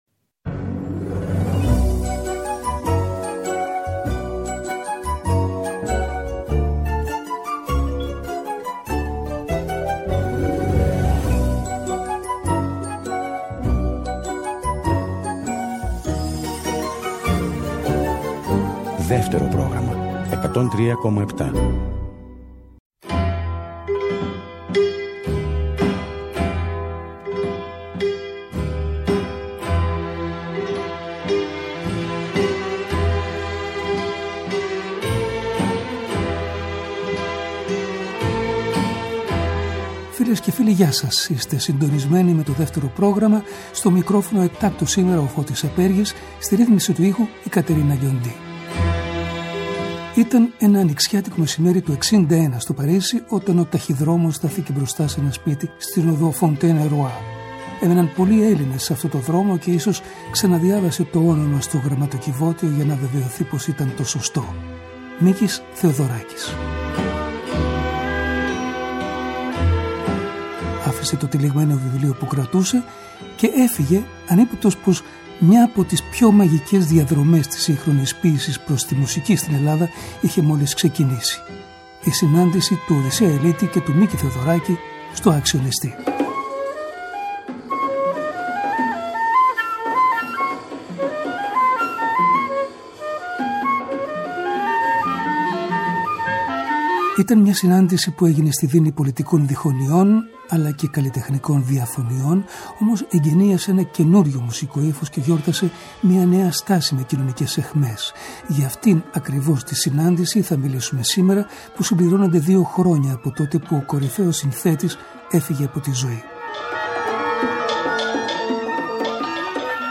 Μια εκπομπή – ντοκουμέντο